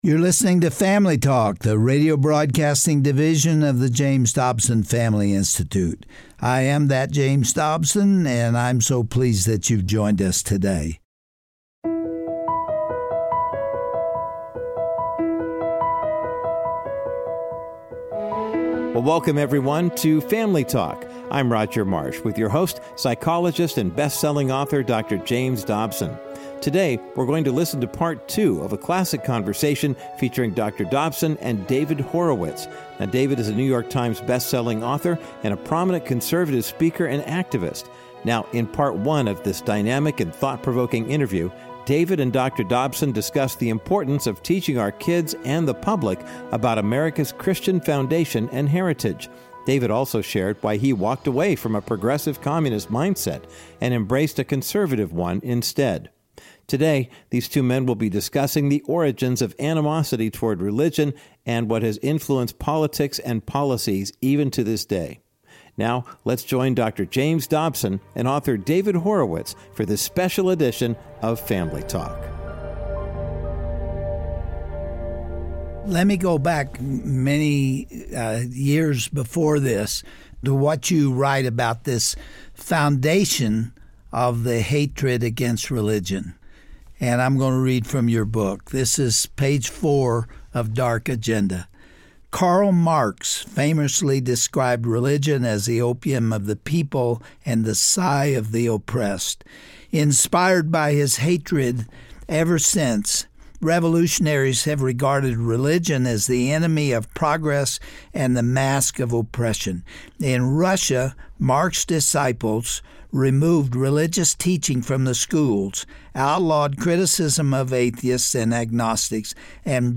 Host Dr. James Dobson
Guest(s): David Horowitz